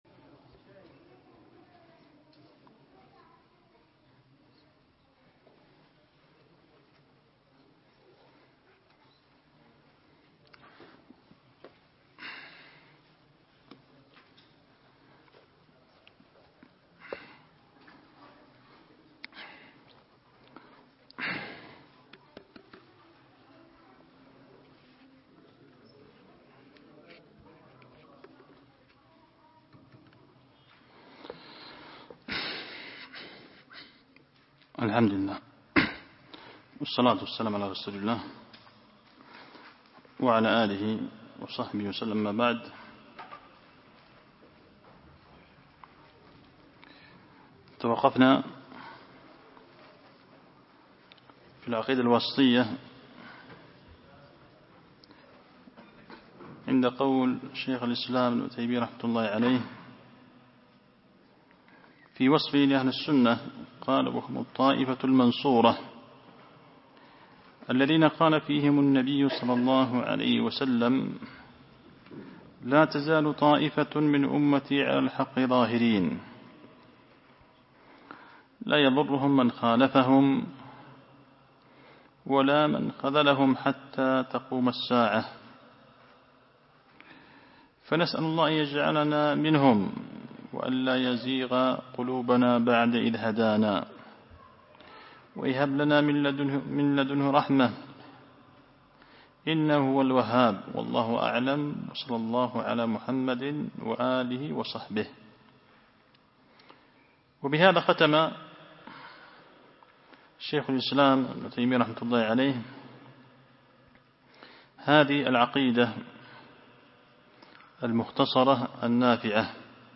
225- التنبيهات السنية الدرس الأخير تم بحمد الله.mp3